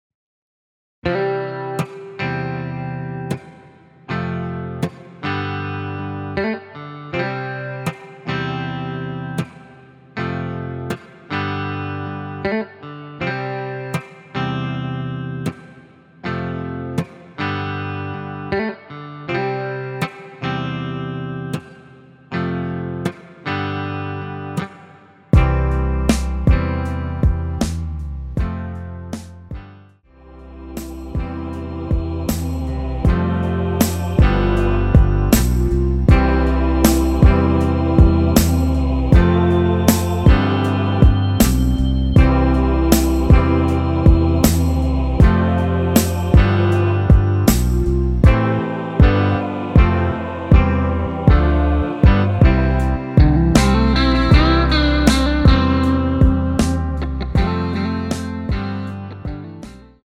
원키에서 (-2)내린 MR 입니다.
앞부분30초, 뒷부분30초씩 편집해서 올려 드리고 있습니다.
중간에 음이 끈어지고 다시 나오는 이유는
곡명 옆 (-1)은 반음 내림, (+1)은 반음 올림 입니다.